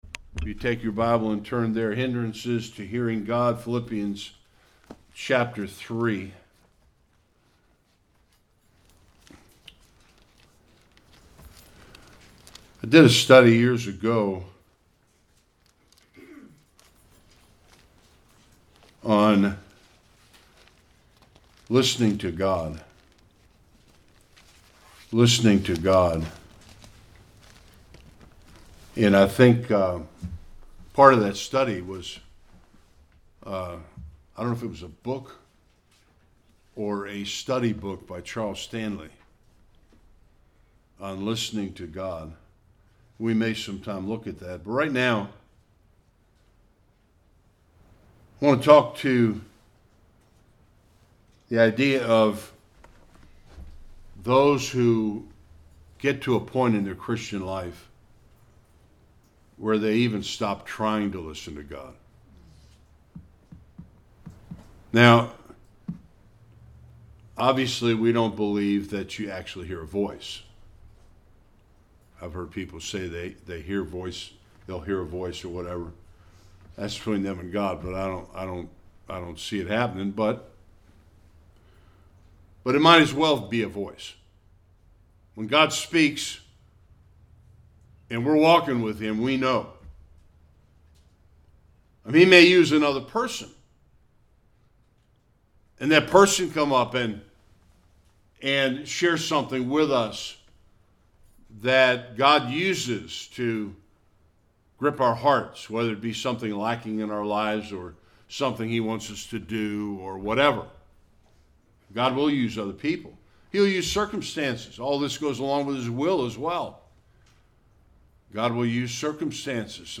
Various Passages Service Type: Sunday Worship How do we hear God?